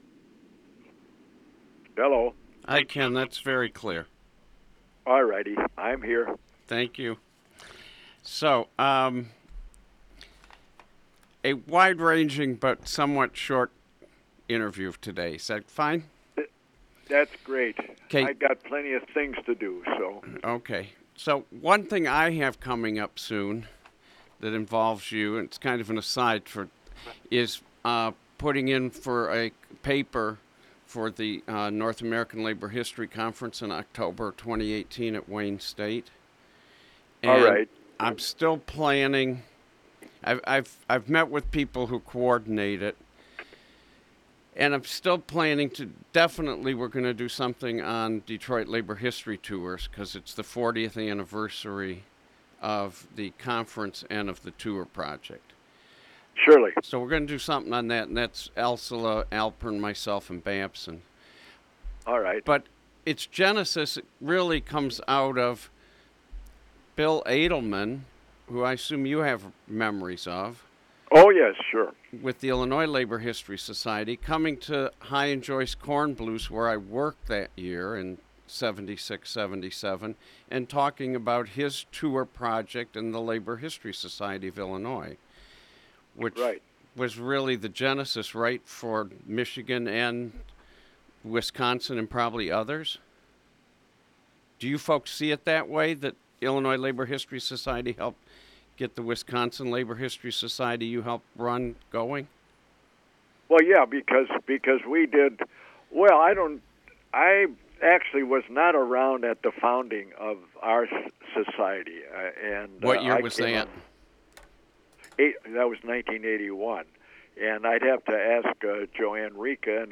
Labor leader
via telephone